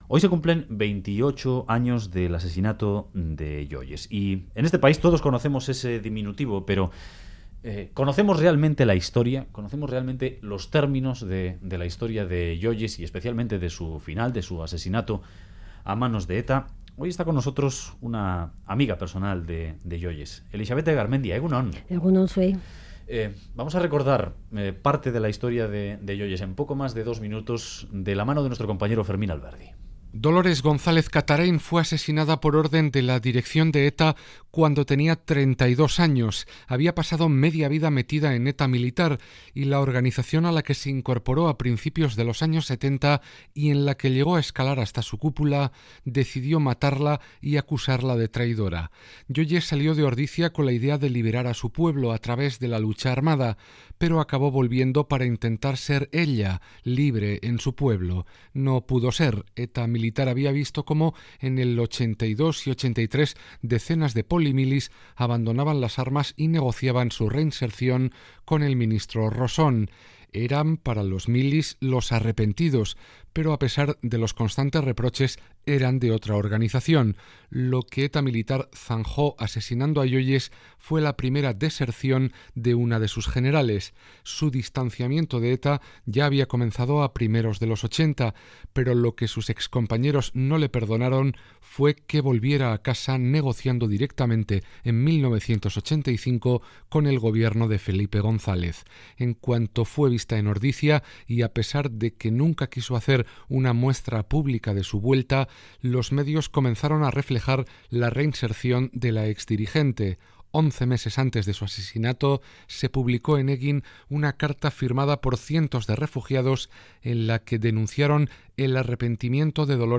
En entrevista al Boulevard de Radio Euskadi